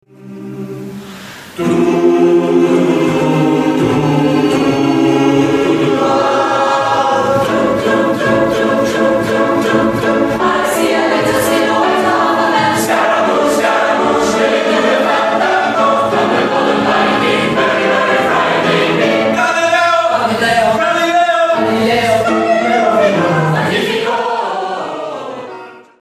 konzertausschnitt_musikstudierende-is1.mp3